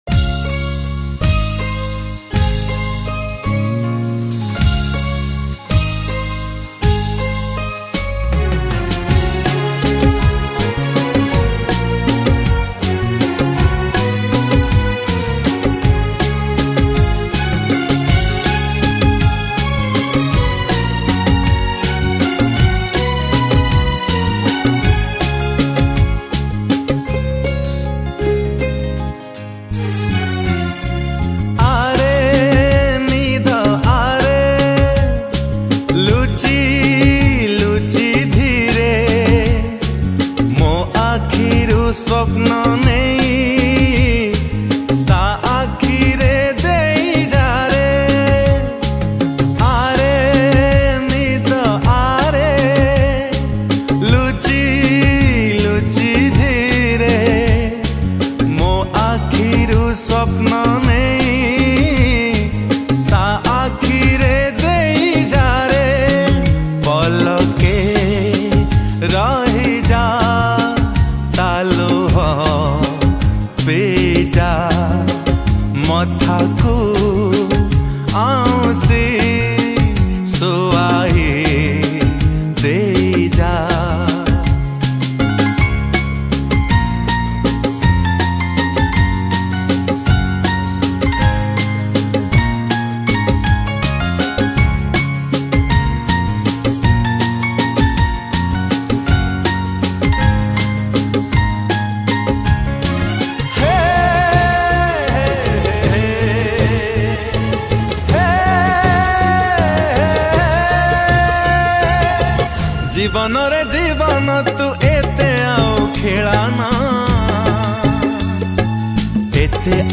Odia Jatra Song Songs Download